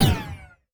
etfx_explosion_magic.wav